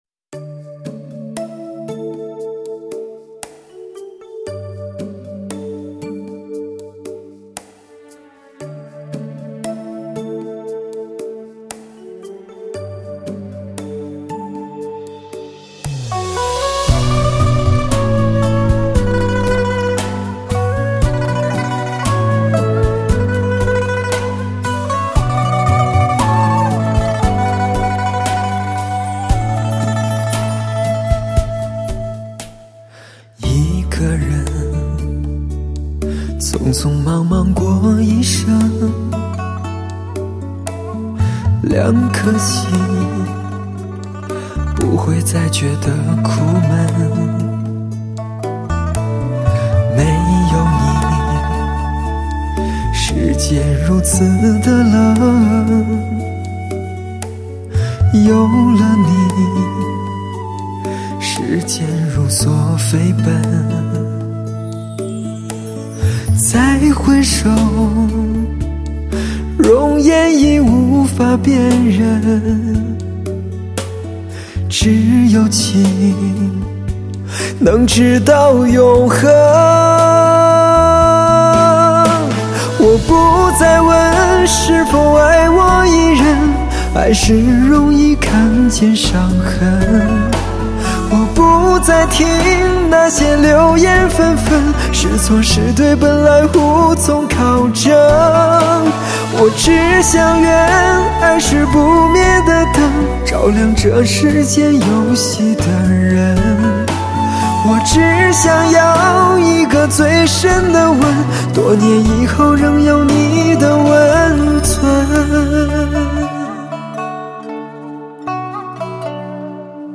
发烧精品-发烧音乐系列
首席疗伤音乐男声